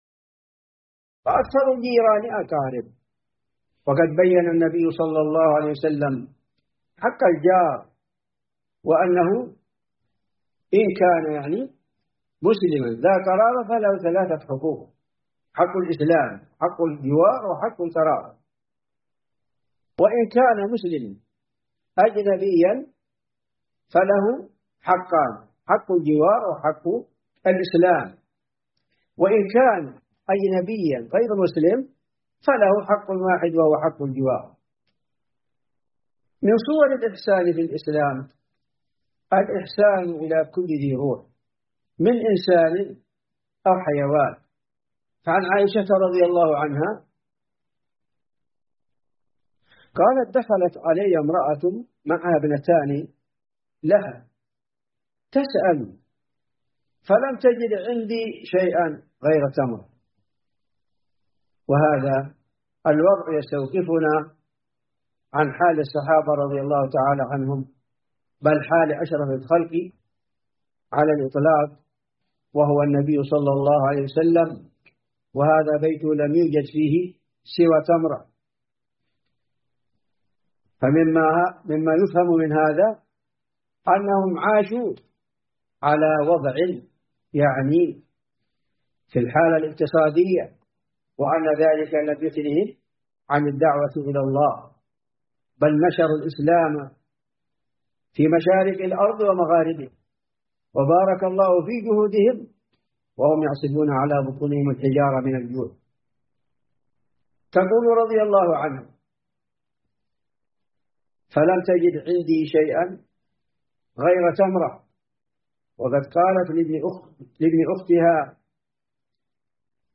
مواعظ ورقائق